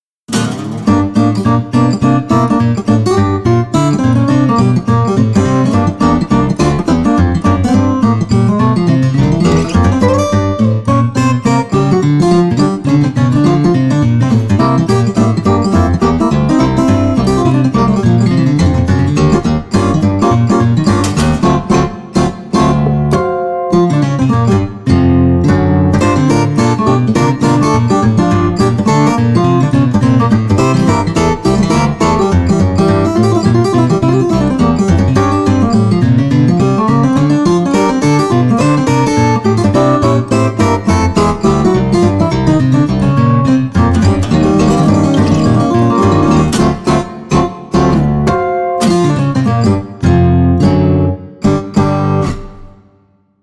アコースティックでジャジーなサウンドもあります。